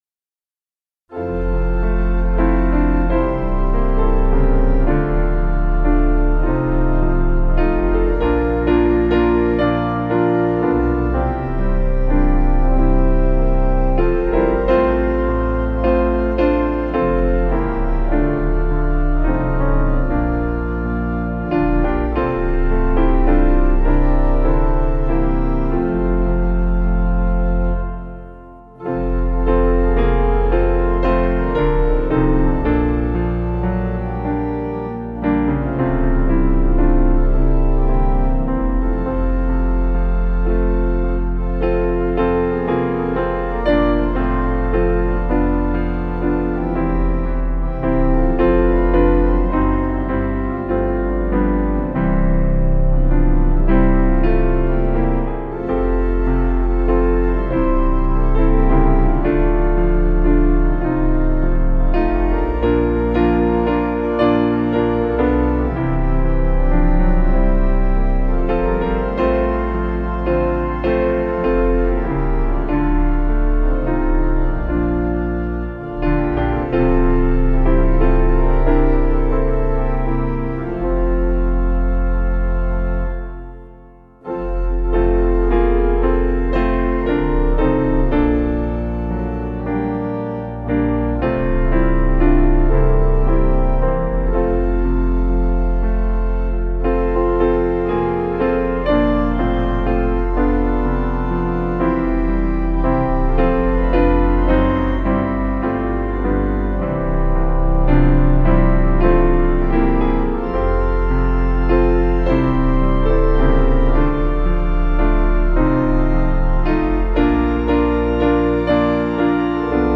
Organ/Piano Duet